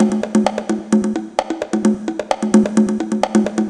130_bongo_4.wav